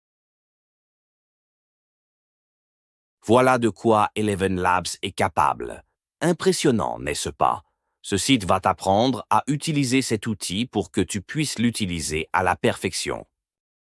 ElevenLabs est une IA qui génère des voix à partir d’un texte et bien plus.
Essayez une des voix d'ElevenLabs
Free-Text-to-Speech-_-AI-Voice-Generator-_-ElevenLabs-_1_.mp3